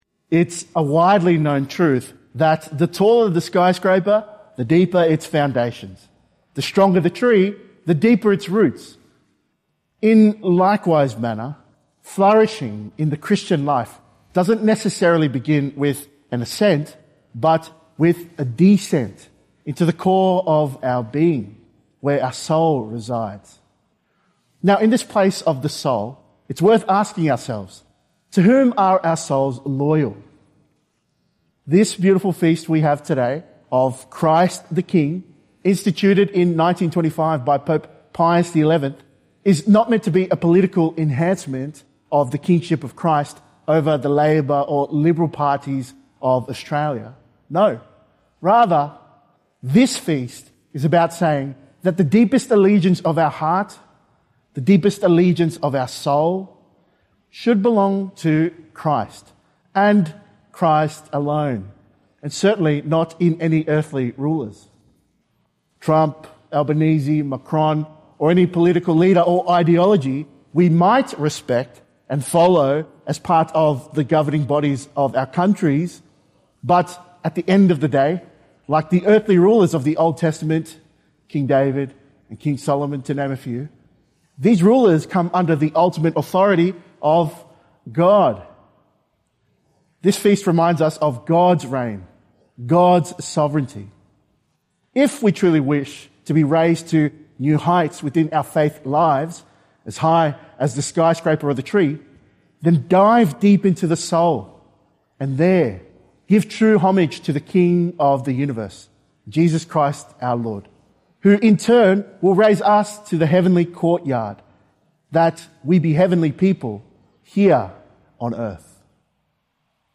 Feast of Christ the King - Two-Minute Homily